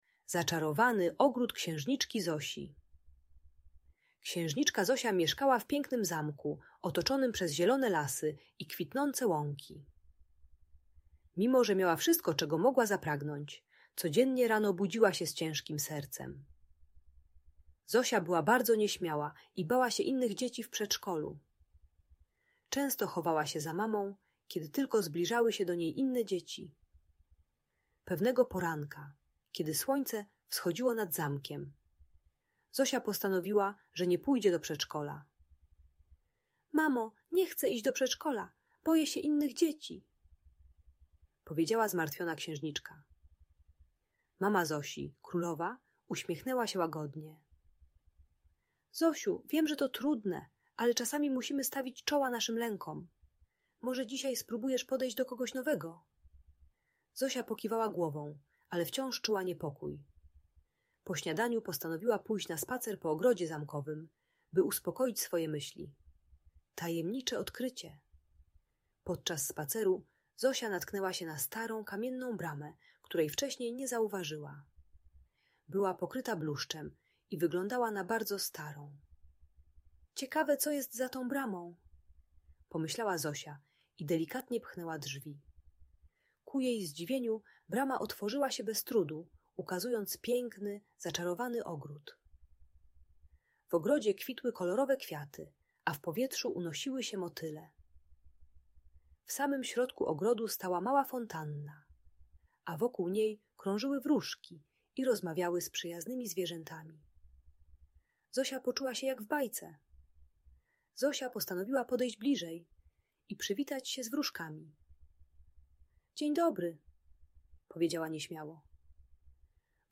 Zaczarowany Ogród Księżniczki Zosi - Magiczna Opowieść - Audiobajka dla dzieci